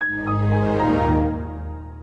windowsXP_shutdown.ogg